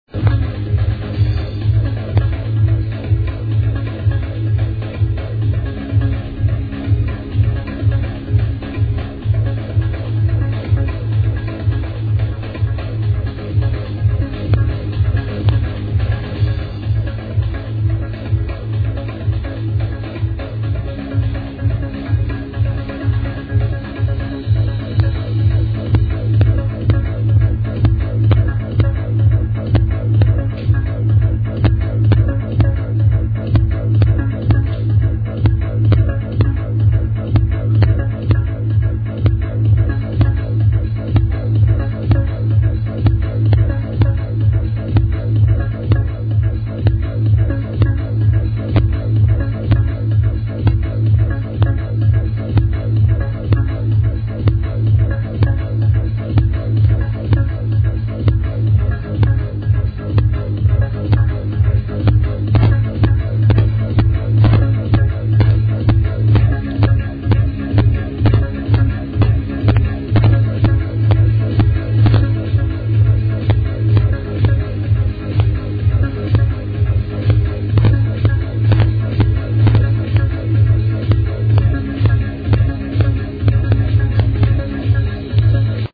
sorry 4 the bad recording...